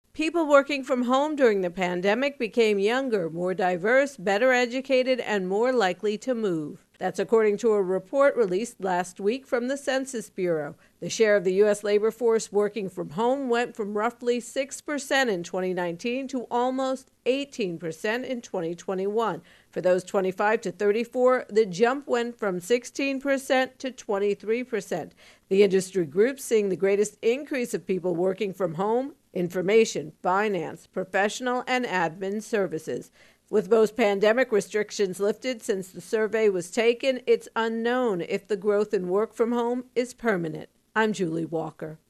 reports on Census Working from Home